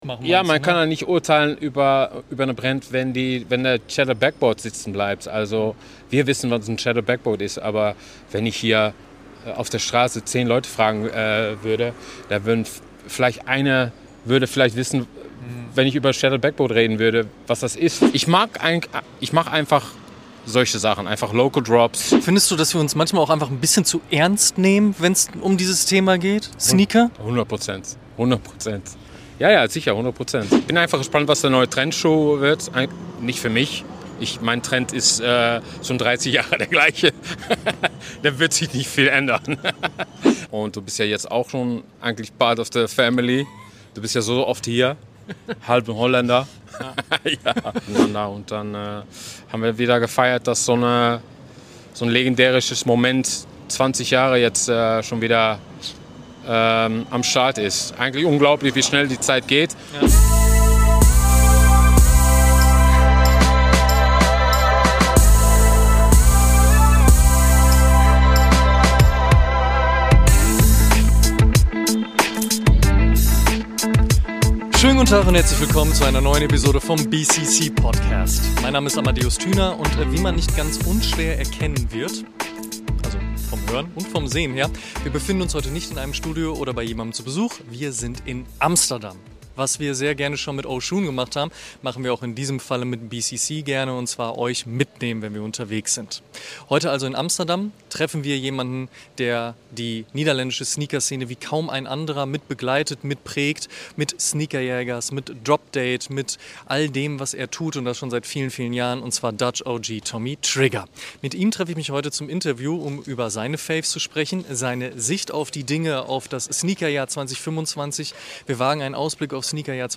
Live aus Amsterdam